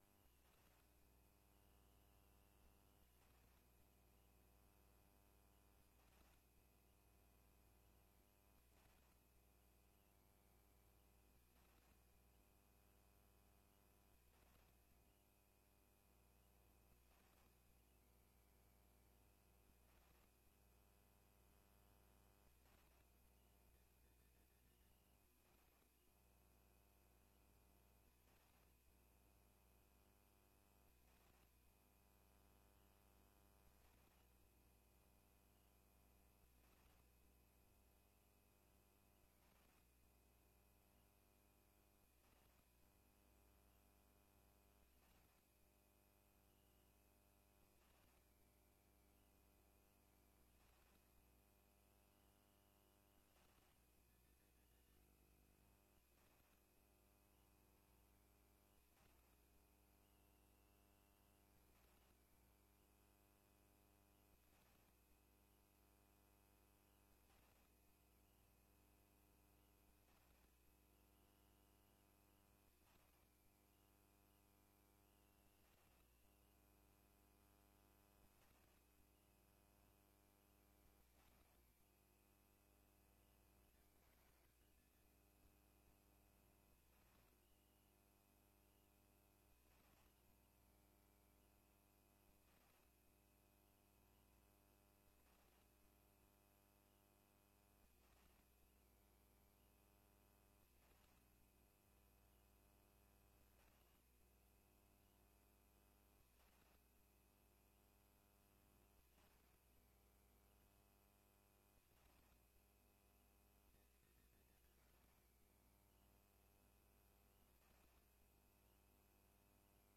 Gemeenteraad 28 november 2024 19:30:00, Gemeente Bergen N-H
Hierbij nodig ik u uit voor de vergadering van de gemeenteraad op 28 november 2024, aanvang 19:30 uur in De Beeck te Bergen.